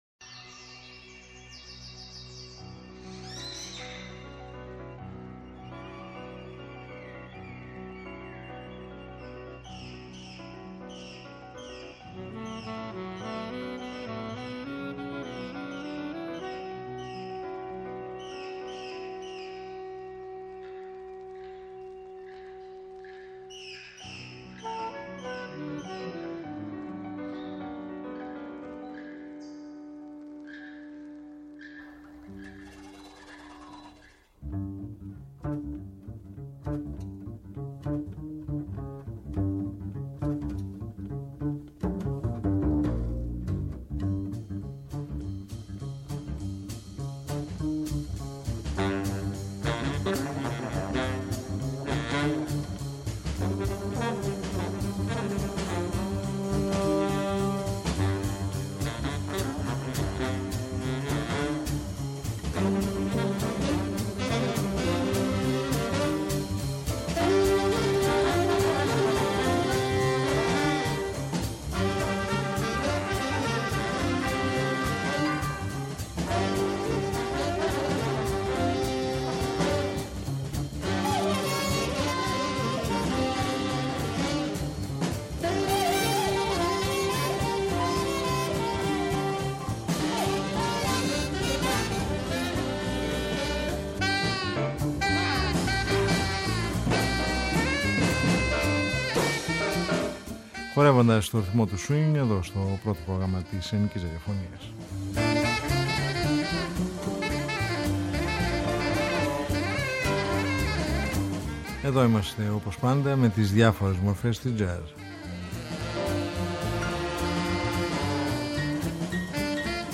Μουσικοί ήχοι εκλεπτυσμένοι με μια διάθεση ασπρόμαυρης ταινίαας δρόμου.